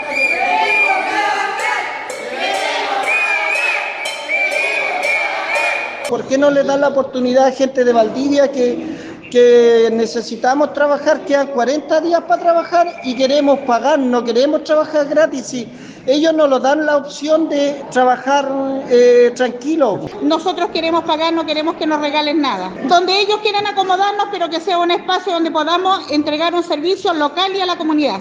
Con pancartas y a viva voz, muy cerca de la oficina de la alcaldía, exigieron que sus permisos sean renovados.
cuna-protesta-comerciantes.mp3